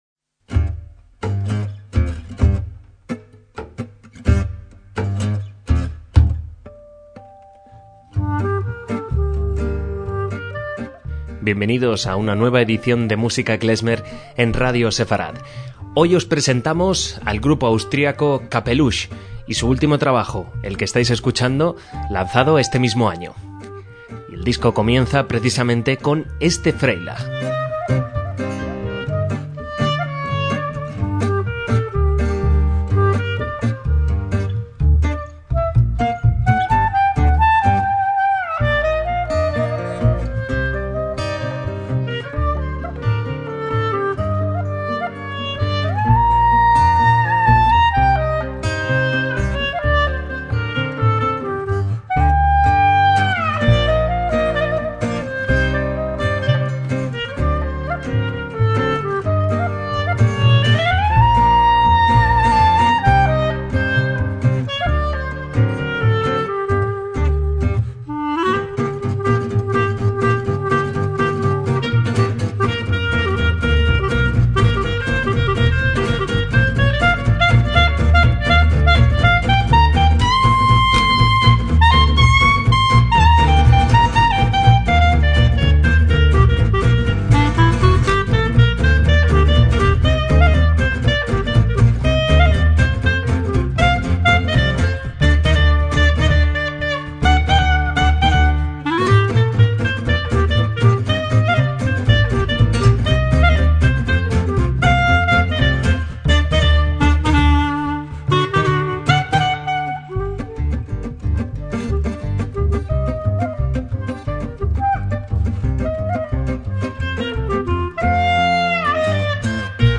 MÚSICA KLEZMER
clarinetista
guitarrista
contrabajista